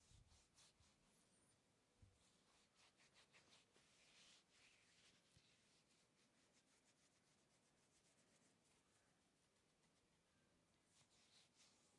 挠挠头
描述：用长指甲抓头。
标签： 刮伤 F ingernail 额头 指甲 指甲 划痕 钉子 划伤 头骨 OWI 头发 划伤
声道立体声